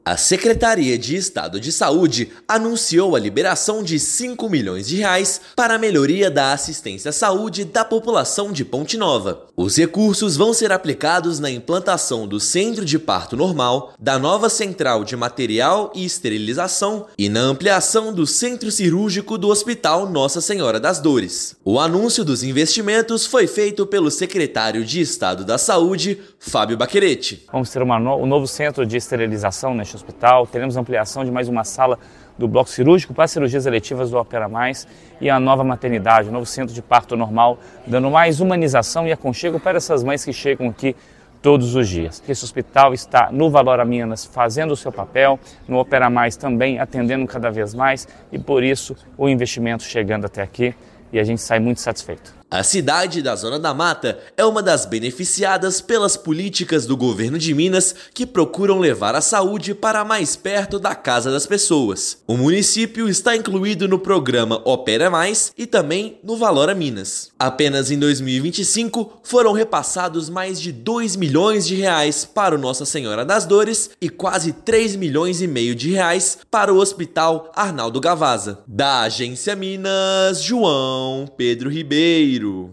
Montante será direcionado à implantação do Centro de Parto Normal, nova Central de Material e Esterilização e ampliação do Centro Cirúrgico. Ouça matéria de rádio.